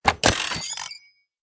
telephone_hang_up.ogg